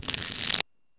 スケルチ